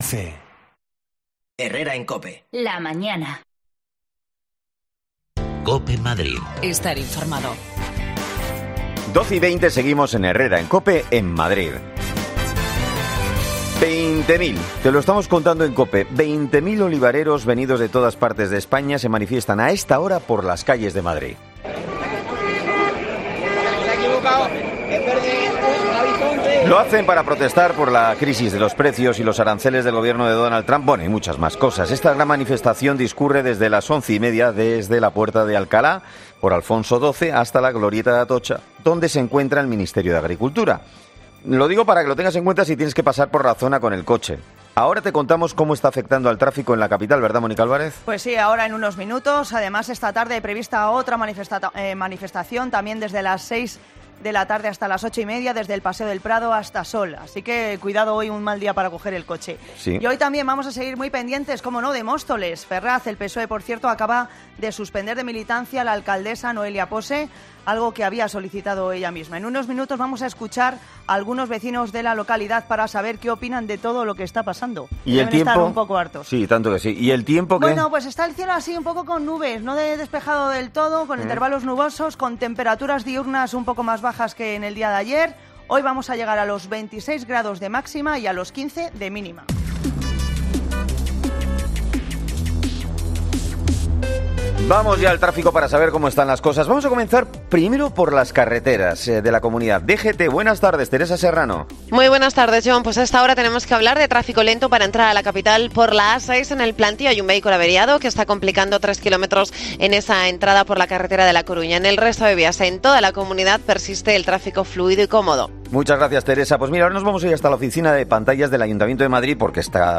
AUDIO: Los olivareros cortan Madrid y los vecinos de Móstoles opinan sobre su alcaldesa Noelia Posse, suspendida de militancia por el Psoe.